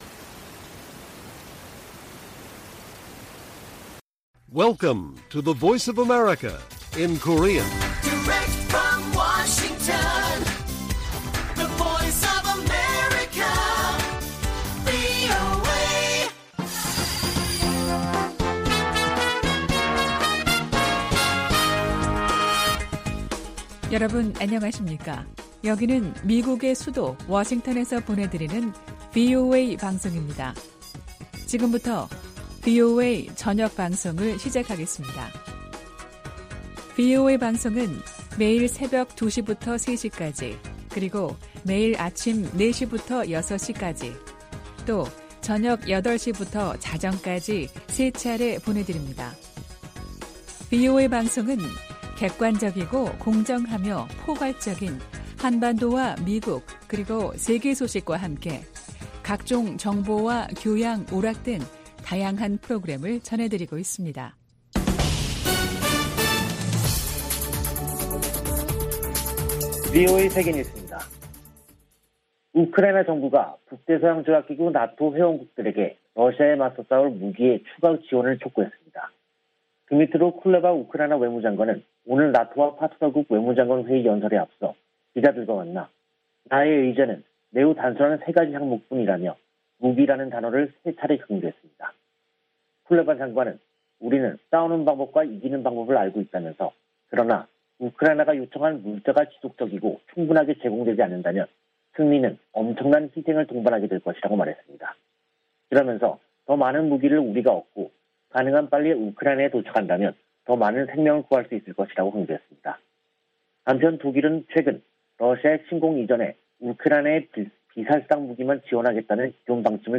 VOA 한국어 간판 뉴스 프로그램 '뉴스 투데이', 2022년 4월 7일 1부 방송입니다. 웬디 셔먼 미 국무부 부장관은 핵무장한 북한은 중국의 이익에도 부합하지 않는다며, 방지하기 위한 중국의 협력을 촉구했습니다. 미 국방부는 한국 내 전략자산 배치와 관련해 한국과 협력할 것이라고 밝혔습니다. 윤석열 한국 대통령 당선인이 캠프 험프리스를 방문해 북한의 핵과 미사일 위협에 강력 대응 의지를 밝혔습니다.